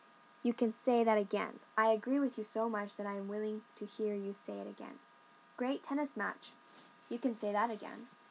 「まさにその通り」 英語ネイティブによる発音は下記のリンクをクリックしてください。